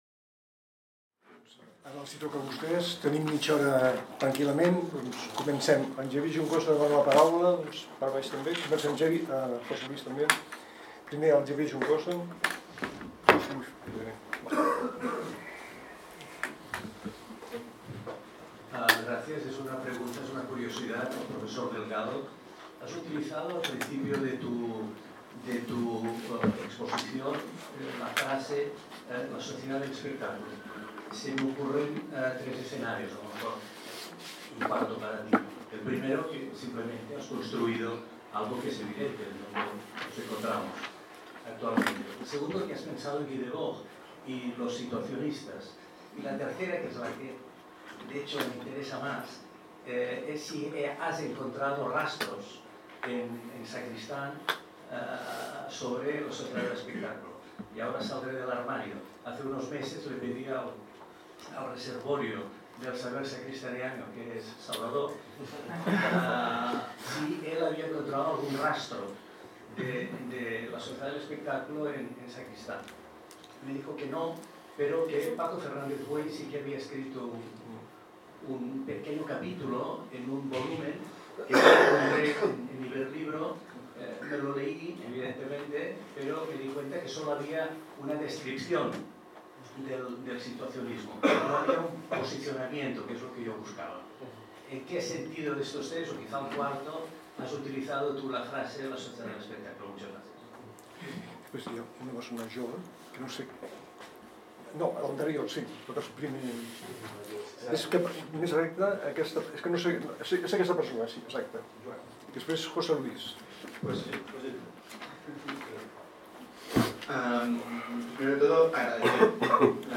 Debat sessió 8
>  Vuitè debat que s'emmarca dins el Simposi Trias 2025, organitzat per la Càtedra Ferrater Mora, en col·laboració amb el Memorial Democràtic i dedicat al filòsof Manuel Sacristán.